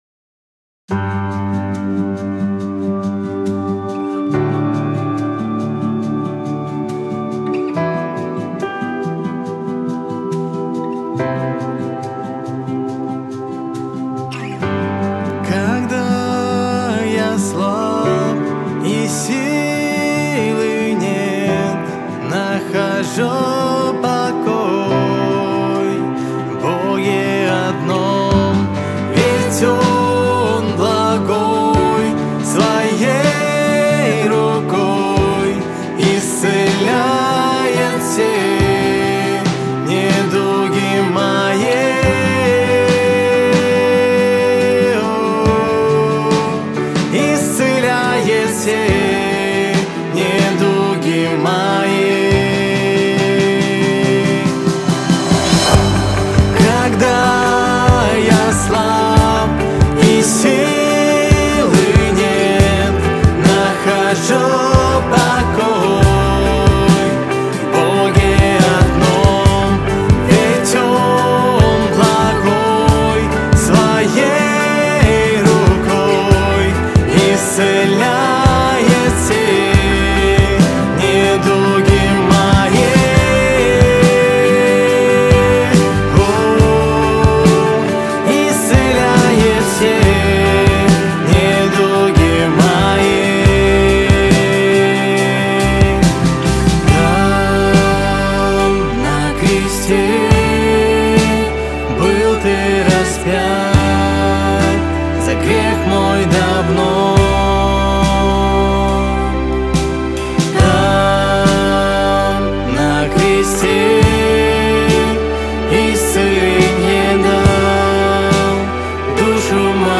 172 просмотра 250 прослушиваний 13 скачиваний BPM: 70